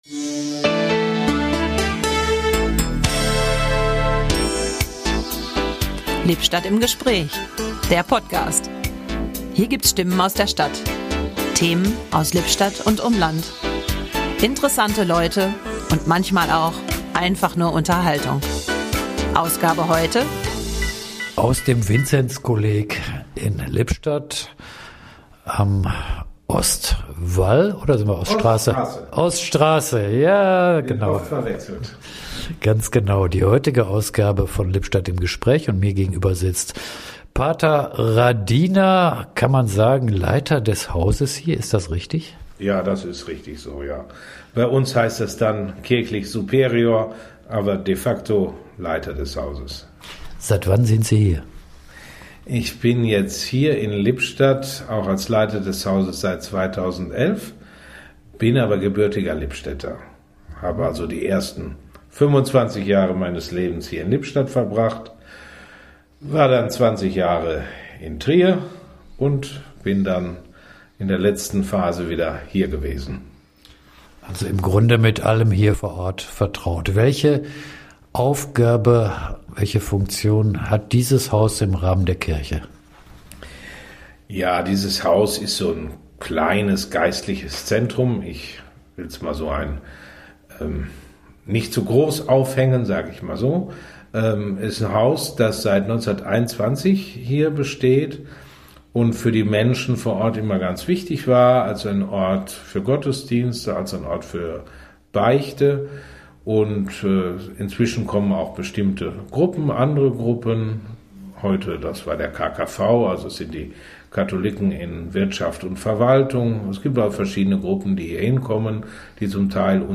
In dieser kurzen Episode sind wir im Vinzenzkolleg in Lippstadt und